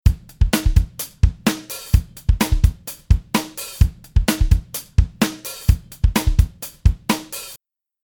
Real drum sound dry